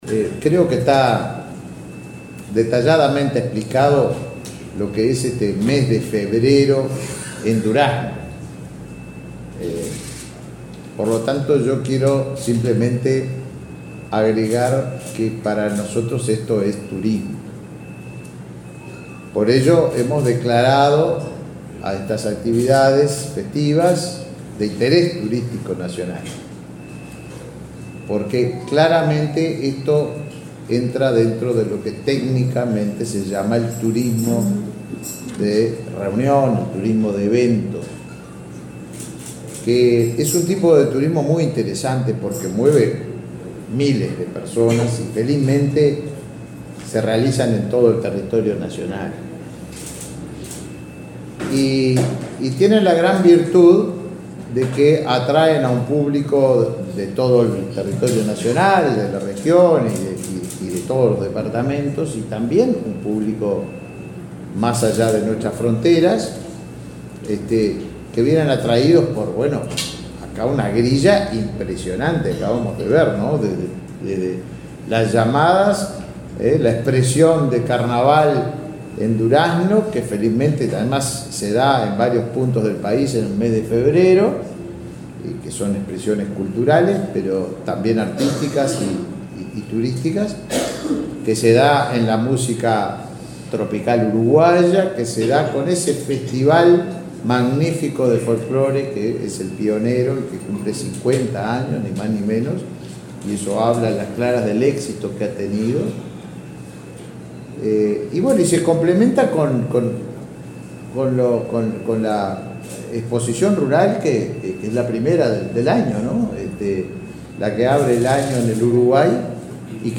Palabras del ministro de Turismo, Tabaré Viera
Palabras del ministro de Turismo, Tabaré Viera 16/01/2024 Compartir Facebook X Copiar enlace WhatsApp LinkedIn El ministro de Turismo, Tabaré Viera, participó, este martes 16 en la sede de su cartera, en el lanzamiento de la 50.ª edición del Festival Nacional e Internacional del Floclore y Encuentro Gaucho, que se desarrollará en Durazno en el mes de febrero.